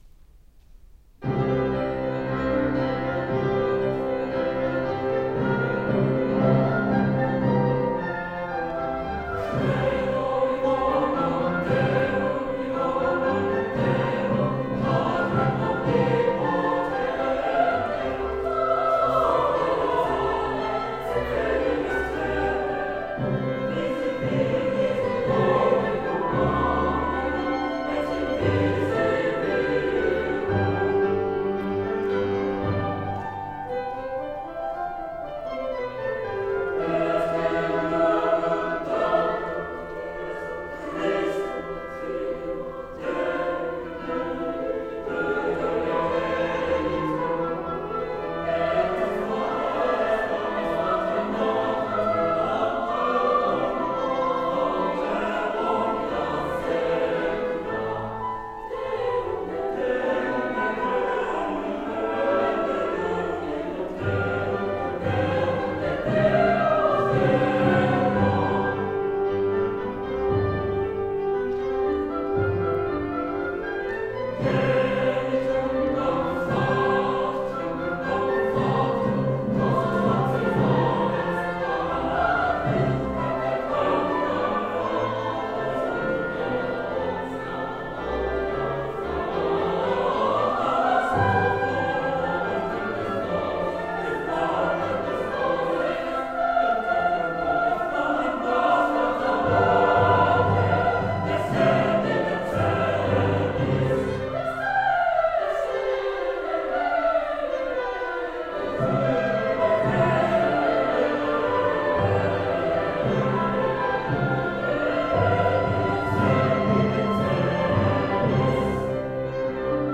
第３２回演奏会音源の公開棚
KYRIE 6.3 161 　　客席にセットした自前録音機の
破格の高音質mp3に変換した